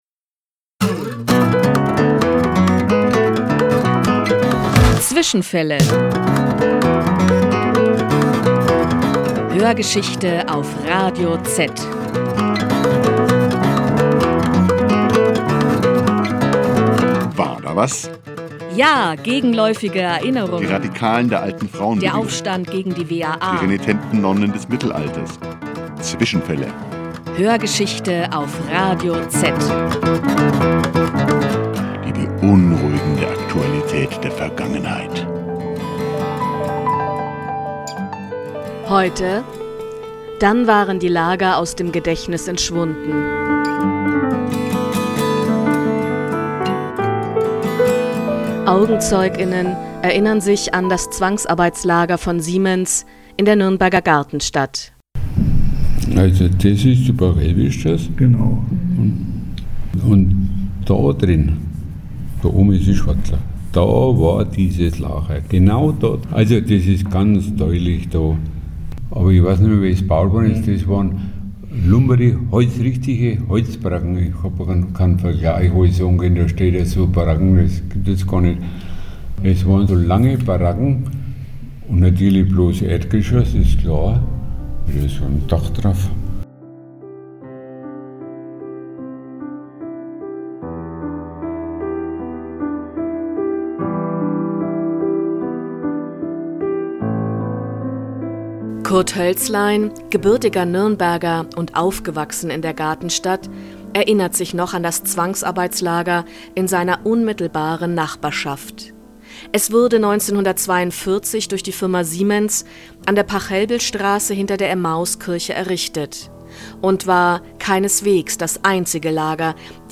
AugenzeugInnen erinnern sich an ein Zwangsarbeitslager von Siemens in der Nürnberger Gartenstadt